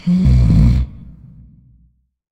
inhale.ogg.mp3